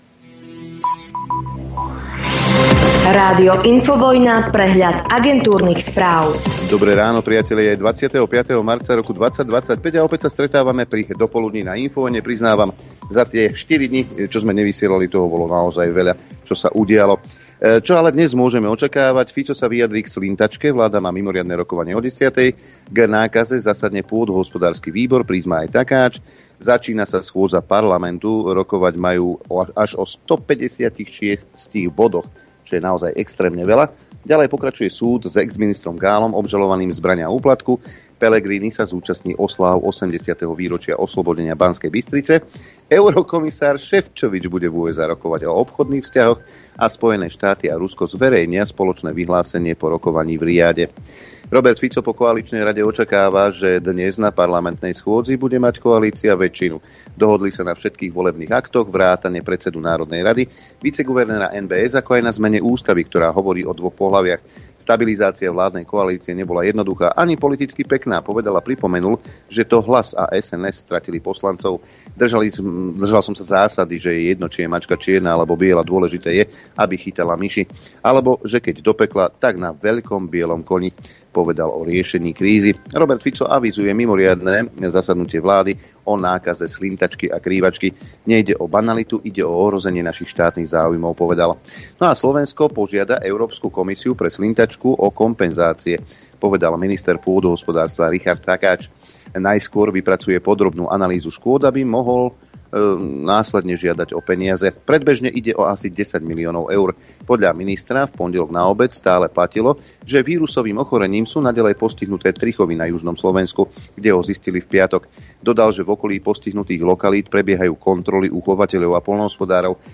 repeat continue pause play stop mute max volume Živé vysielanie 1.